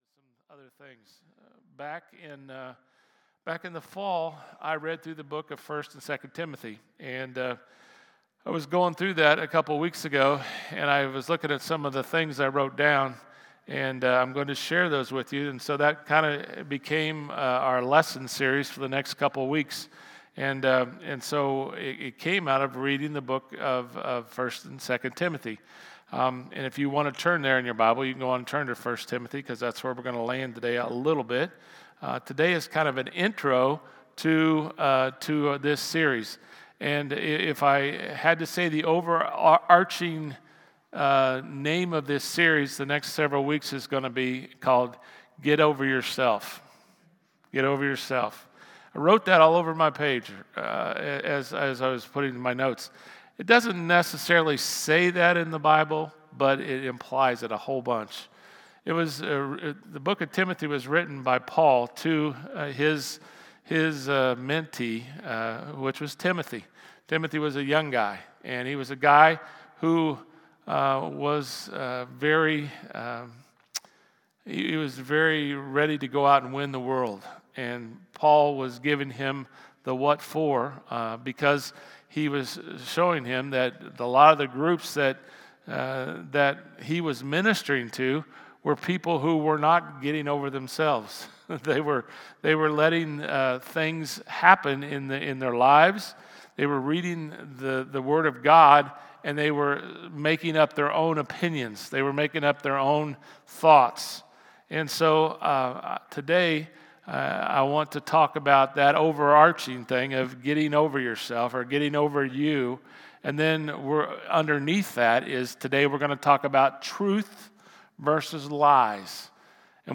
Sermon-4.27.mp3